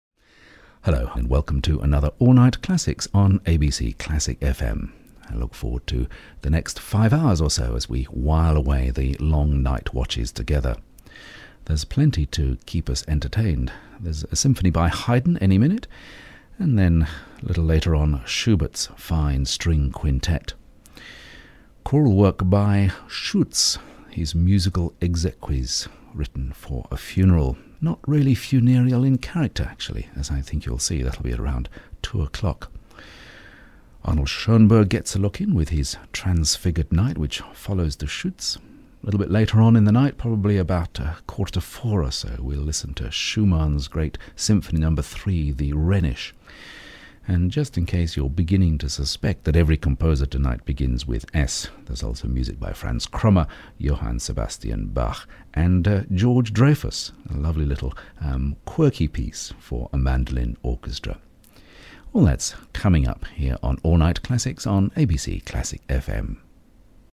Male
English (British)
Older Sound (50+)
Live Announcer
Male Voice Over Talent
0920Live_radio_announcer2.mp3